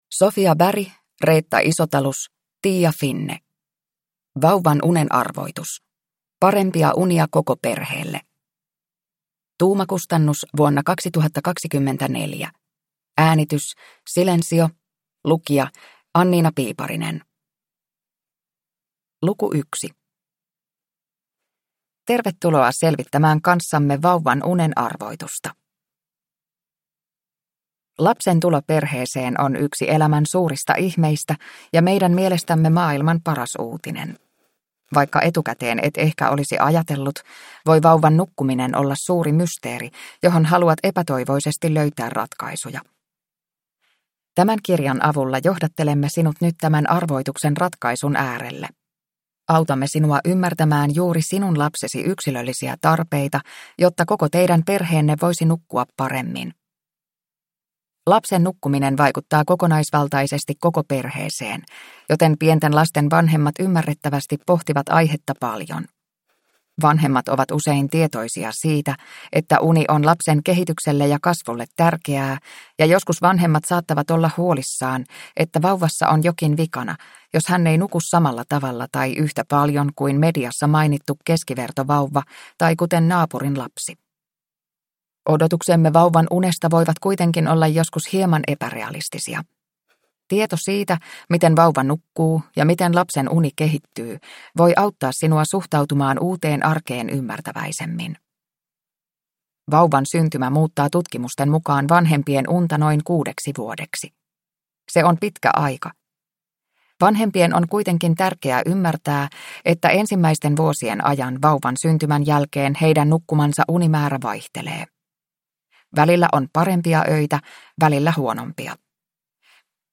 Vauvan unen arvoitus – Ljudbok